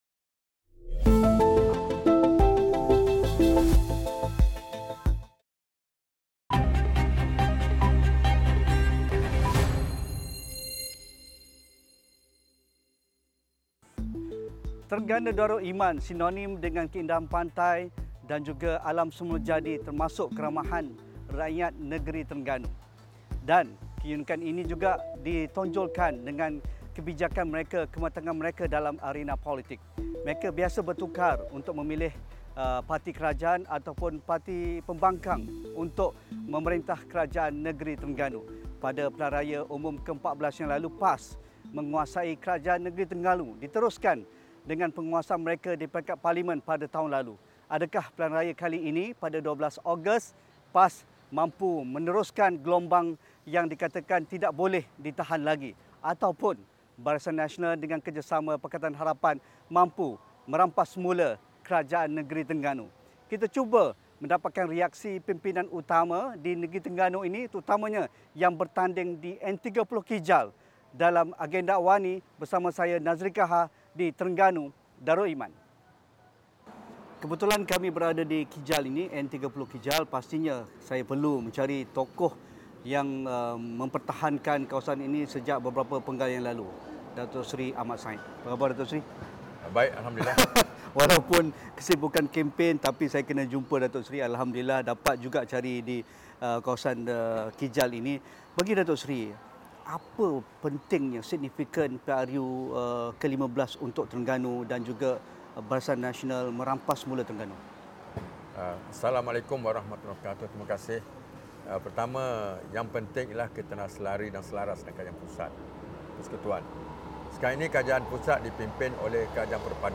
Berdasarkan rekod beberapa pilihan raya lepas dan faktor pertambahan pengundi baharu, adakah Terengganu diramal sebagai ‘swing state’ atau status quo dipertahan? Temu bual bersama calon ‘kerusi panas’ DUN Kijal 9 malam ini.